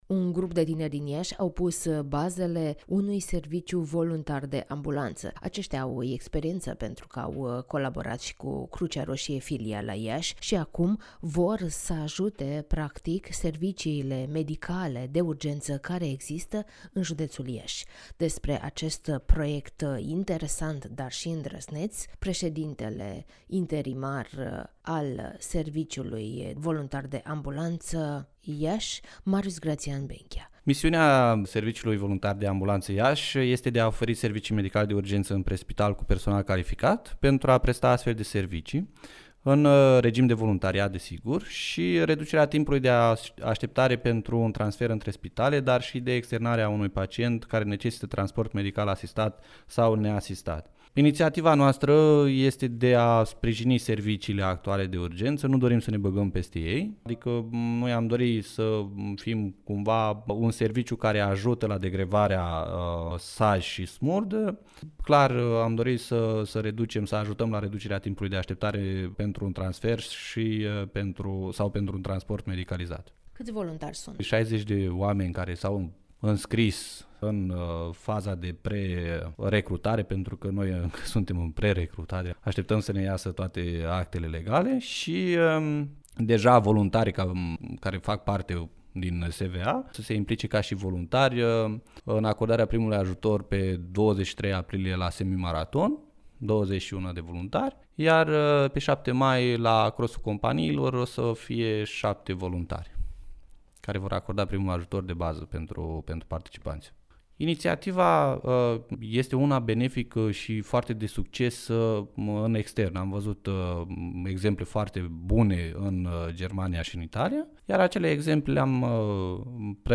(INTERVIU) La Iași va funcționa Serviciul Voluntar de Ambulanță